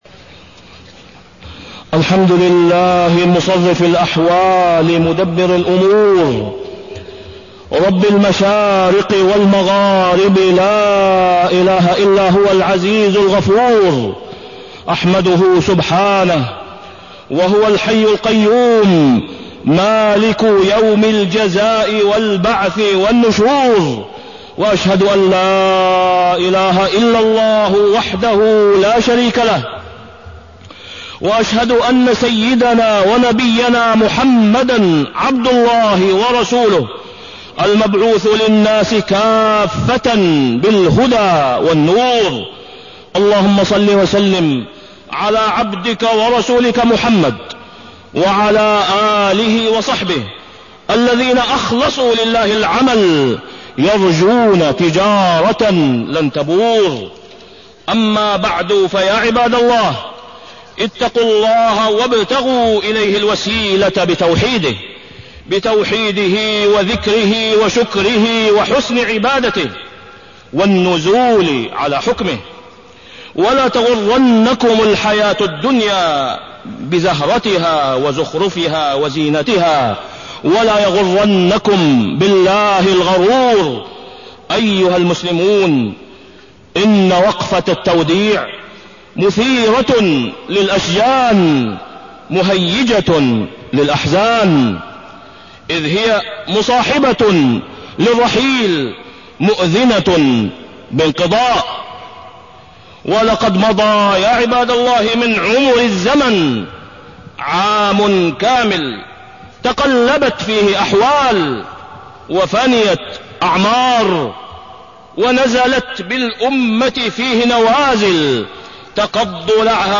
تاريخ النشر ٢٩ ذو الحجة ١٤٢٤ هـ المكان: المسجد الحرام الشيخ: فضيلة الشيخ د. أسامة بن عبدالله خياط فضيلة الشيخ د. أسامة بن عبدالله خياط وداع العام والمحاسبة